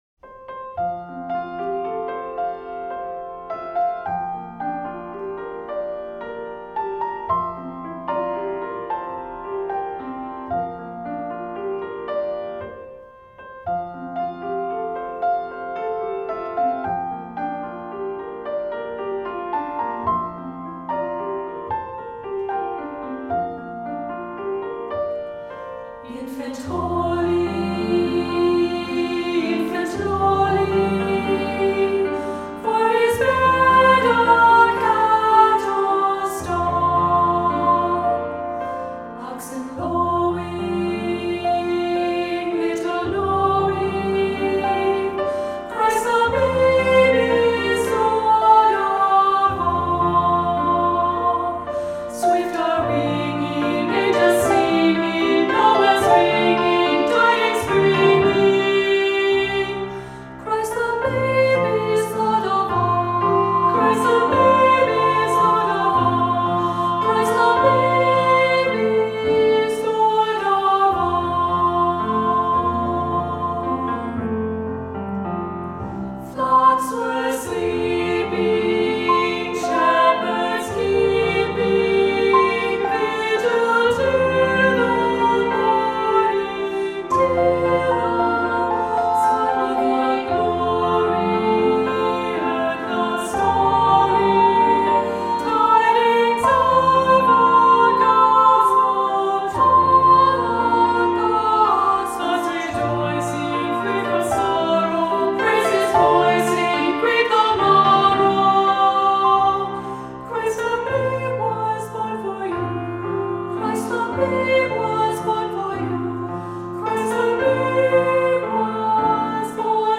Voicing: 2-part Choir